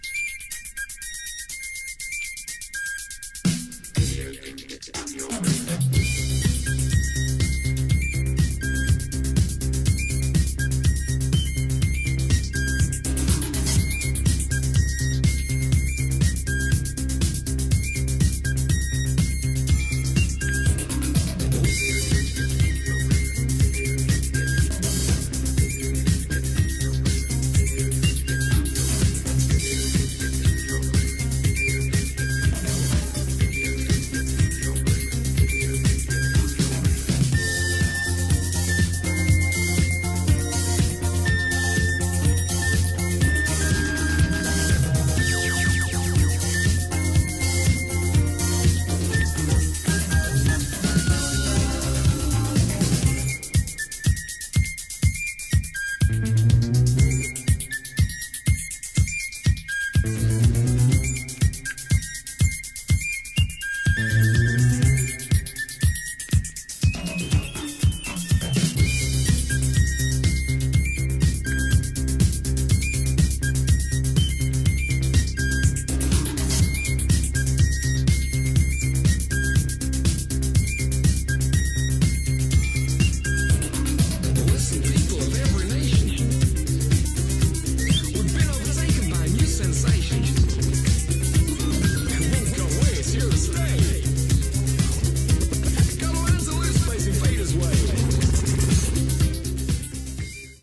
'81年コズミック古典として知られるUKオブスキュア・ディスコ・アンセム！！